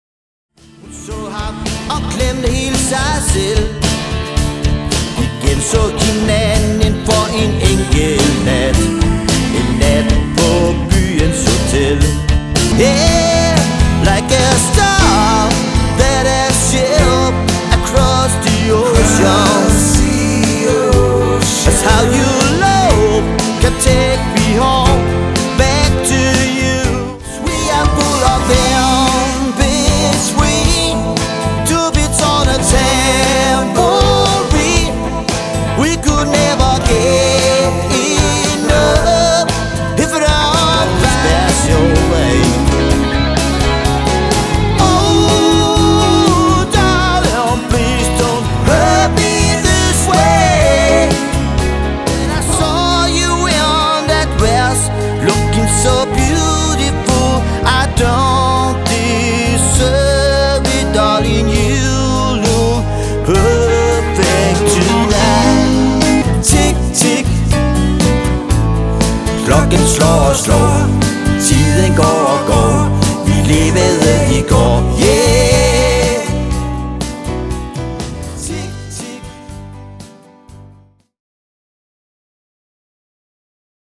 4 personers cover-band fra Nordjylland
• Allround Partyband
• Coverband